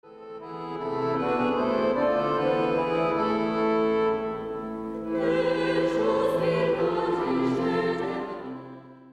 Liberec - Ruprechtice 14.9.2003
Adam Michna z Otradovic: Loutna česká (s LAURUS)